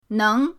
neng2.mp3